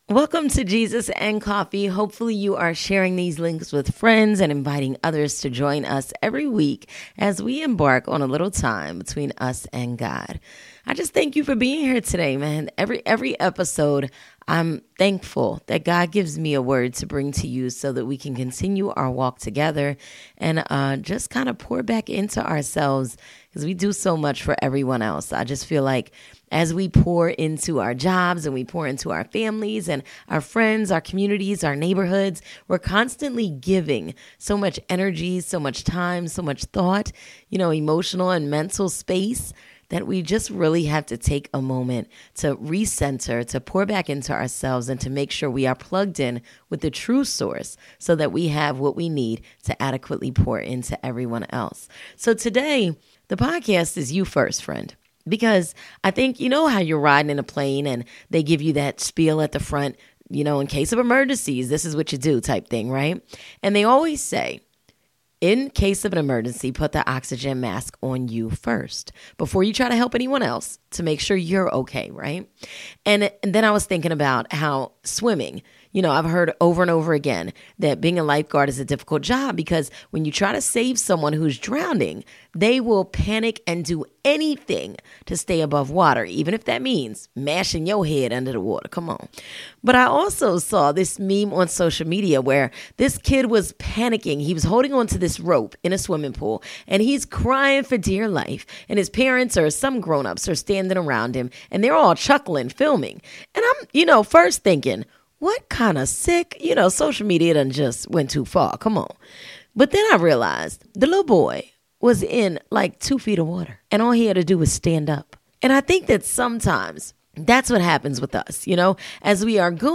Having a conversation with a friend; designed to help sprinkle some upbeat positivity in the listeners day. There will be storytelling, the occasional guest interview, and biblical reference to help set the tone.